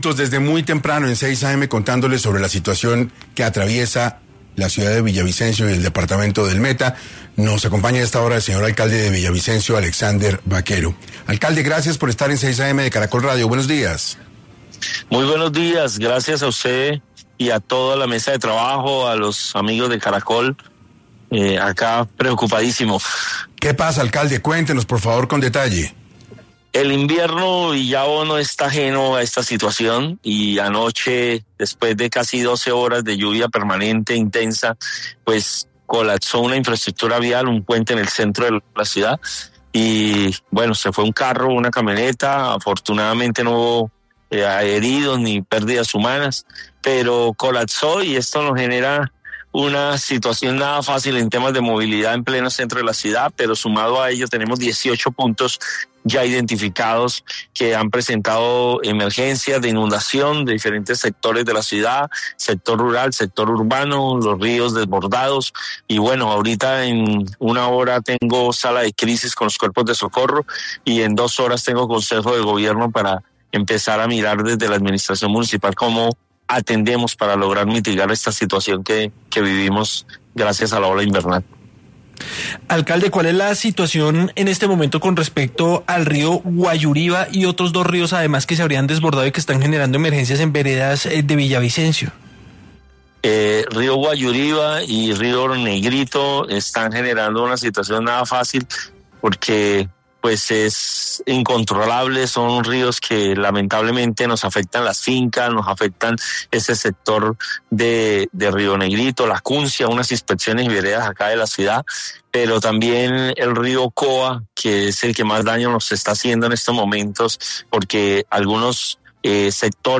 En Caracol Radio estuvo Alexander Baquero, alcalde de Villavicencio, quien dio un balance sobre las inundaciones en Villavicencio: